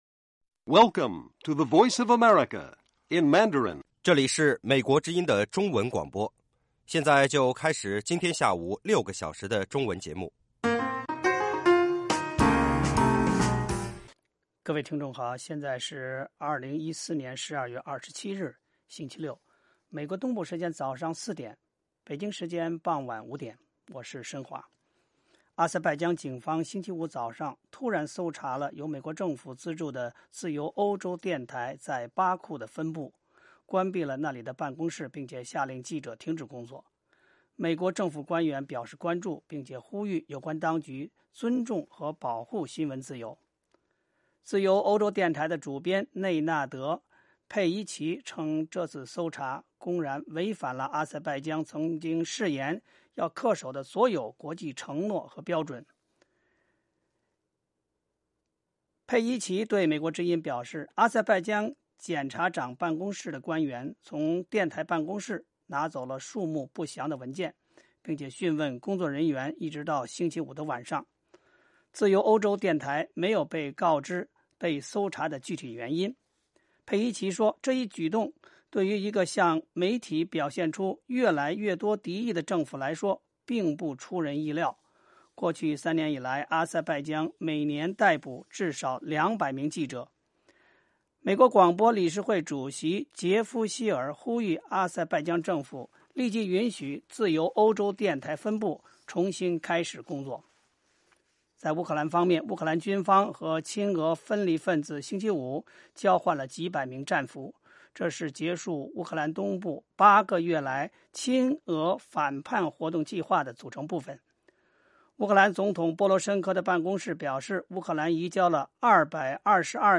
晚5-6点广播节目